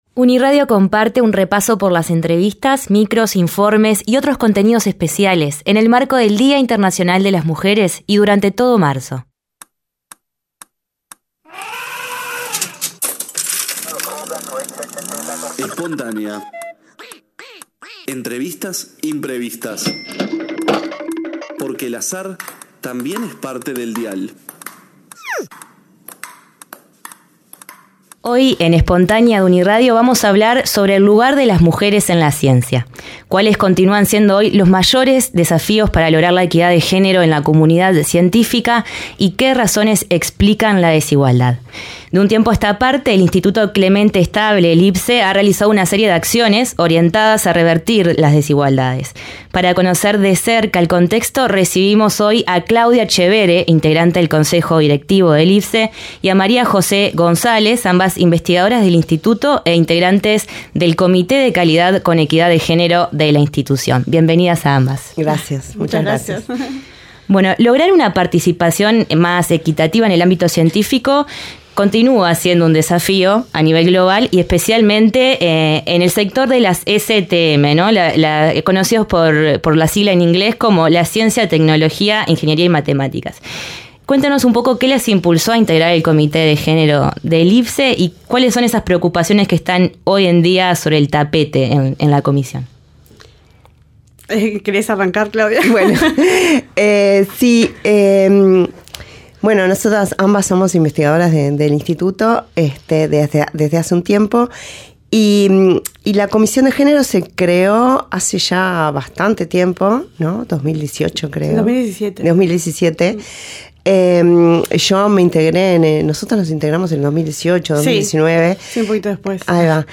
* Entrevista